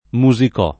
[ mu @ ik 0+ ]